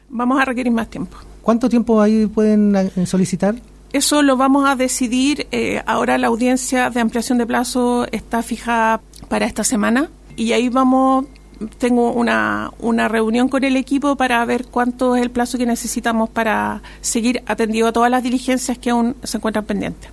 Fue en entrevista con Radio Bío Bío en Puerto Montt, a un año del anuncio de la investigación de oficio que inició el Caso Convenios en Los Lagos, que la fiscal regional Carmen Gloria Wittwer, se refirió a lo que esto ha significado para el Ministerio Público.